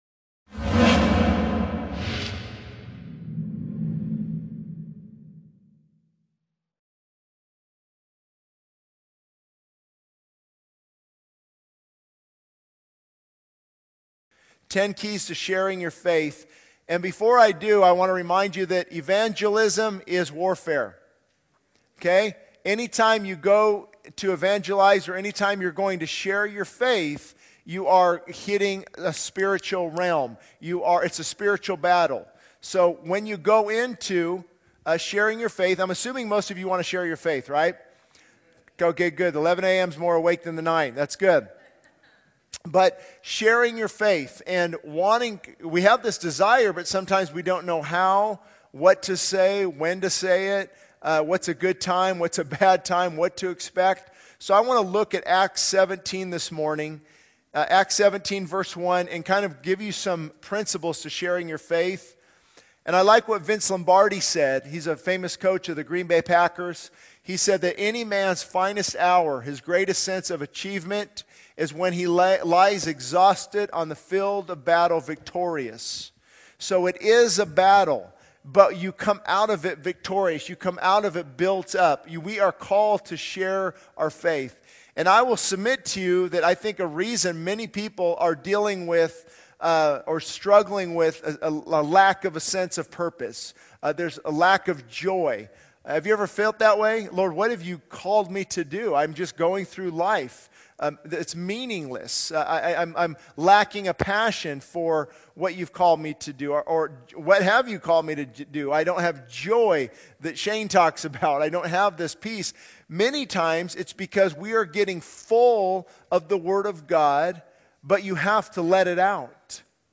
This sermon emphasizes the importance of surrendering to God in evangelism, highlighting the spiritual battle involved in sharing one's faith. It explores Acts 17, providing principles for effective evangelism. The speaker encourages humility, teachability, and being filled with the Holy Spirit to have both authority and power in witnessing.